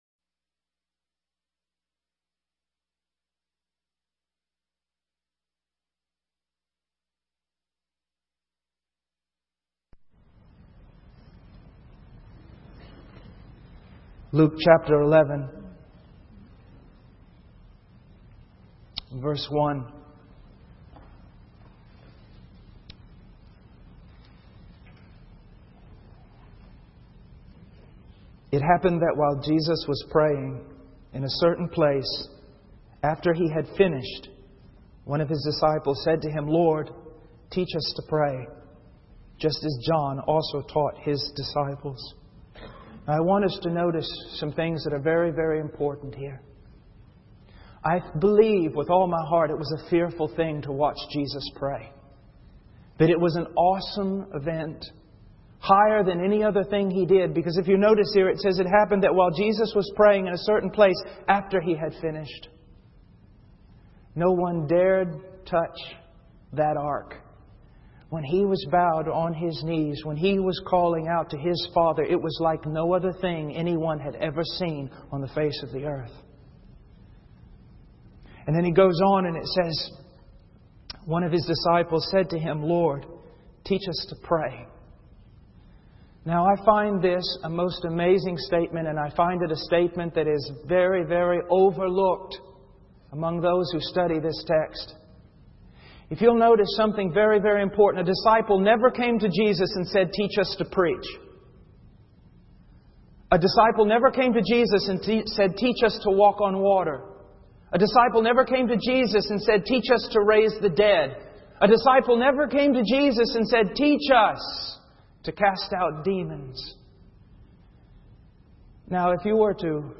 In this sermon, the speaker emphasizes the importance of persevering in prayer and not losing heart. He refers to a parable given by Jesus to demonstrate the need for continuous prayer.